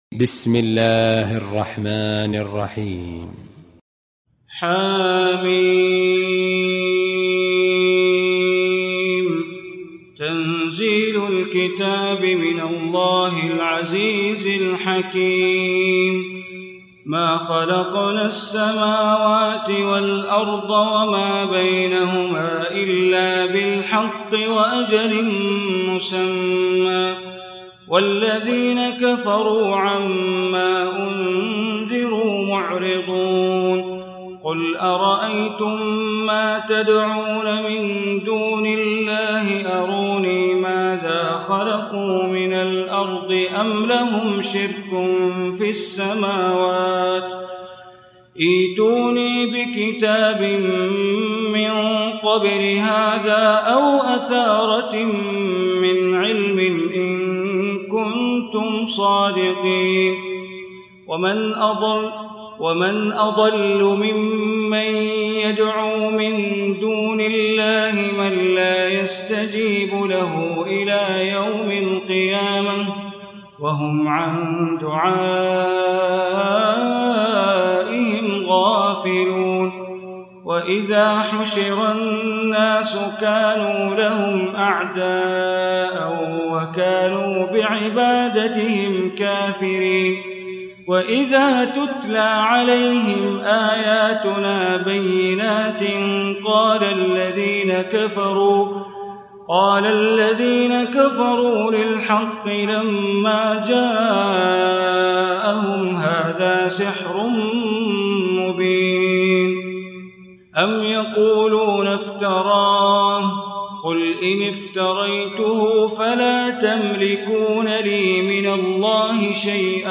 Surah Al-Ahqaf, listen online mp3 tilawat / recitation in Arabic recited by Imam e Kaaba Sheikh Bandar Baleela.